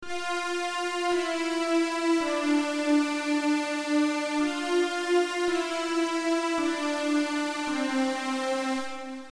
描述：我的歌曲《英雄之旅》中的管弦乐循环。
标签： 110 bpm Hip Hop Loops Orchestral Loops 1.56 MB wav Key : Unknown
声道立体声